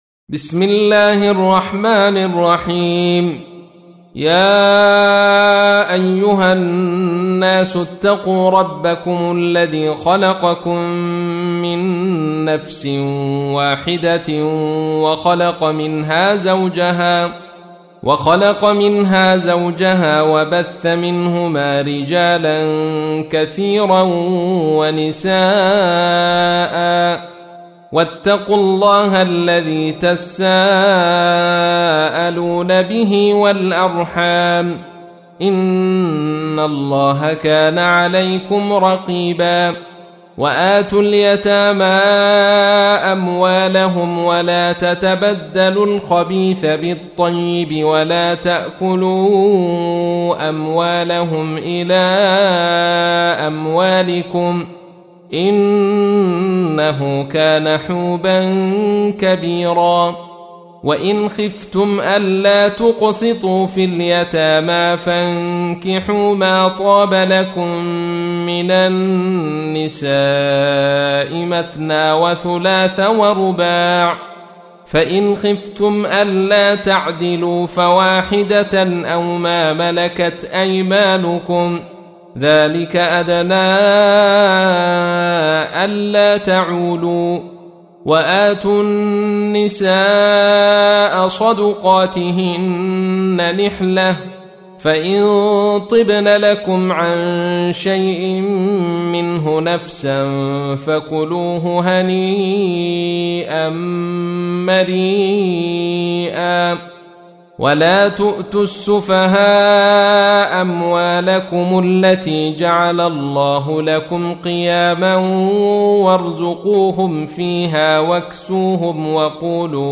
تحميل : 4. سورة النساء / القارئ عبد الرشيد صوفي / القرآن الكريم / موقع يا حسين